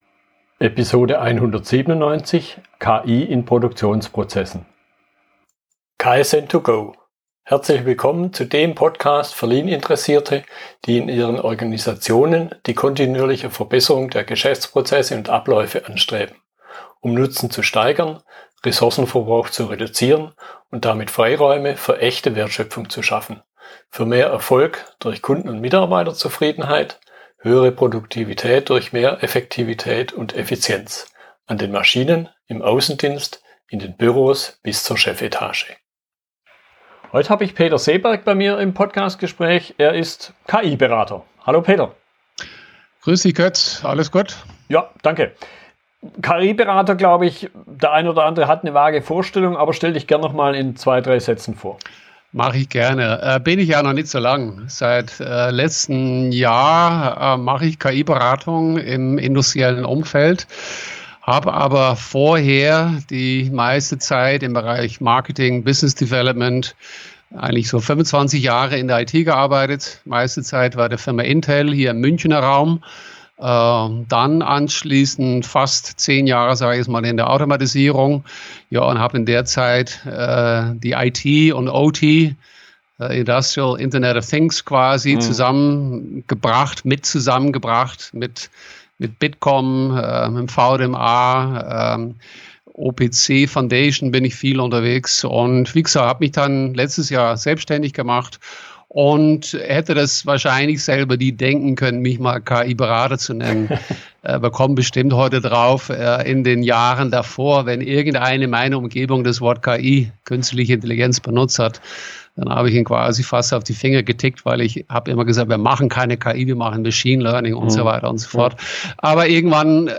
Fragestellungen im Gespräch